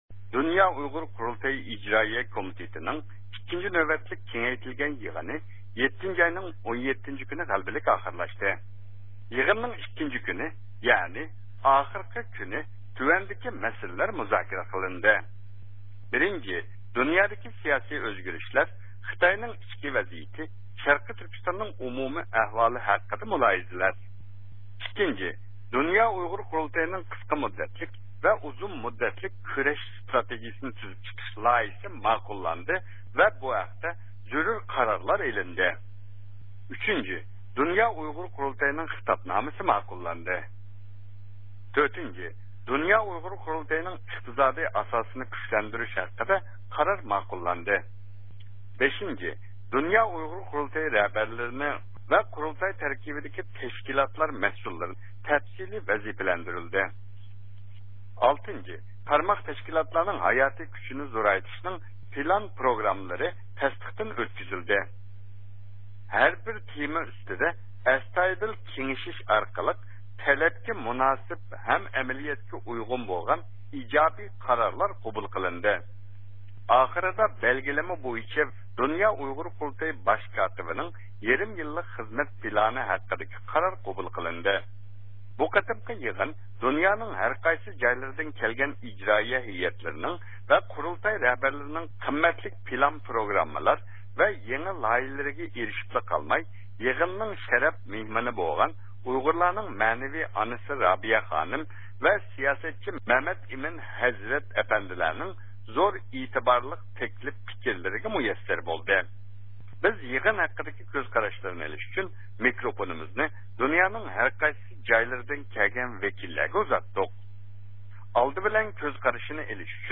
يىغىنغا قاتناشقان بىر قىسىم ۋەكىللەر